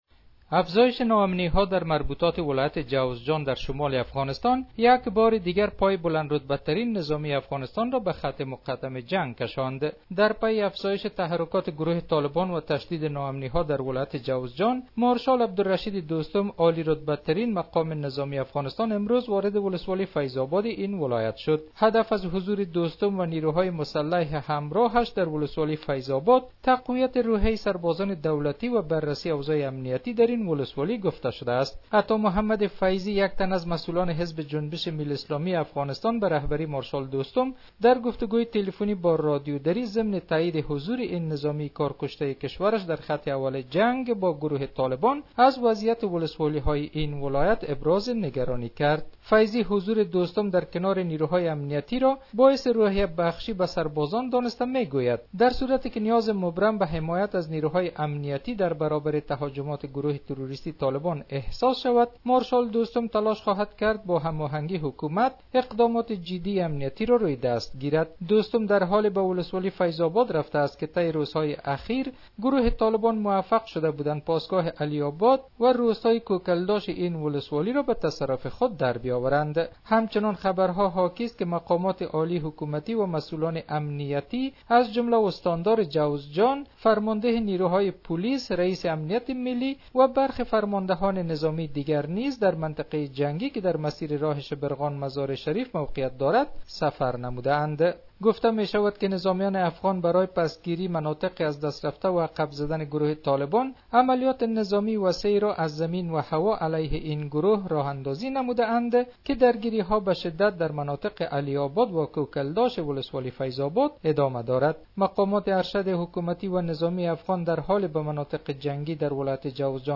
به گزارش خبرنگار رادیو دری ، هدف از حضور دوستم و نیروهای مسلح همراهش درولسوالی فیض آباد تقویت روحیه سربازان دولتی وبررسی اوضاع امنیتی در این ولسوالی گفته شده است .